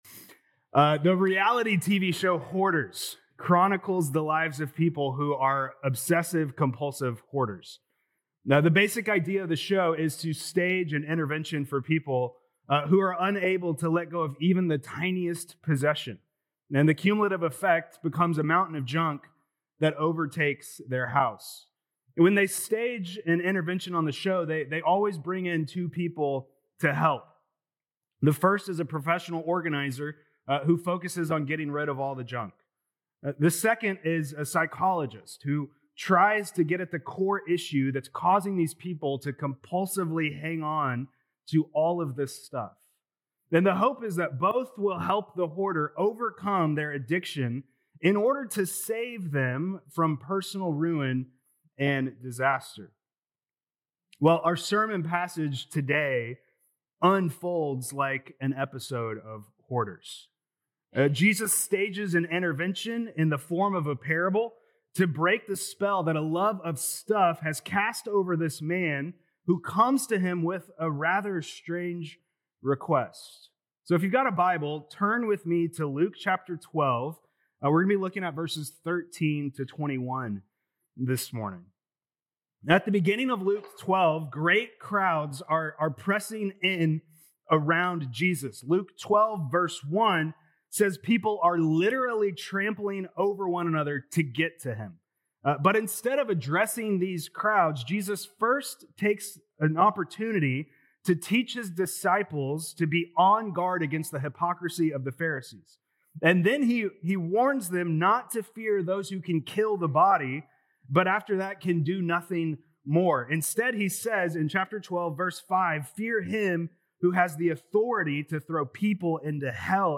Feb 22nd Sermon